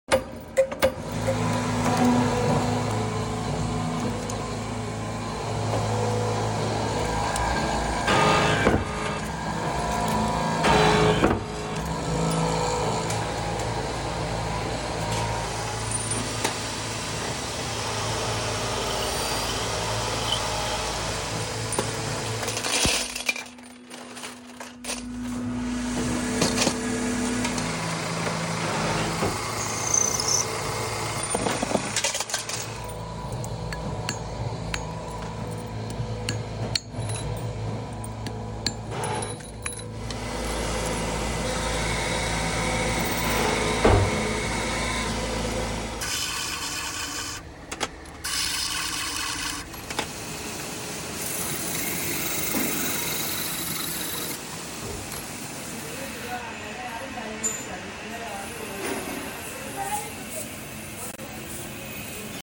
How High Quality Bike Engine sound effects free download